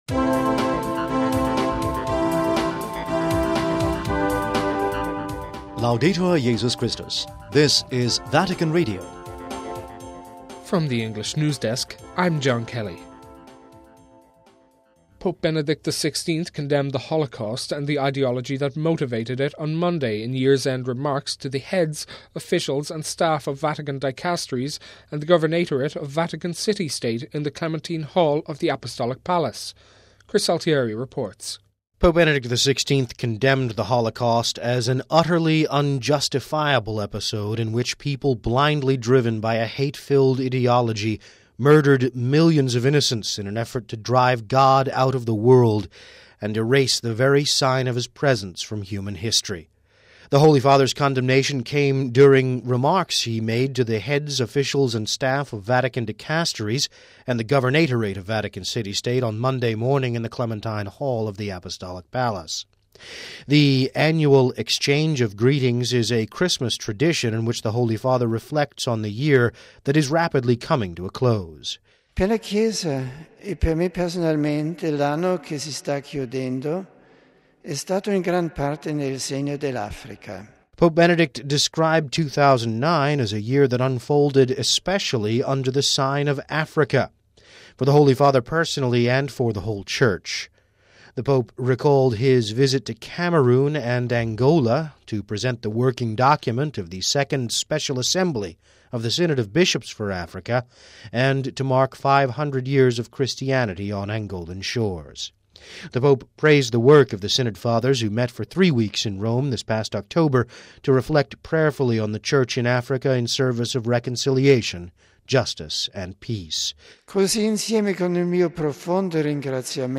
(21 Dec 09 - RV) Pope Benedict XVI condemned the Holocaust and the ideology that motivated it on Monday in year’s end remarks to the heads, officials and staff of Vatican Dicasteries and the Governatorate of Vatican City State Monday morning in the Clementine Hall of the Apostolic Palace. We have this report...